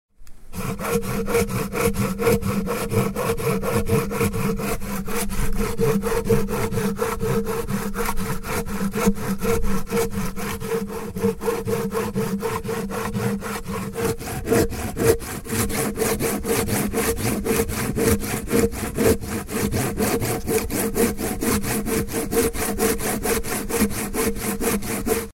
Звуки ножовки
На этой странице собраны звуки ножовки в разных вариациях: от плавных движений по дереву до резких рывков при работе с металлом.